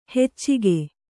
♪ heccige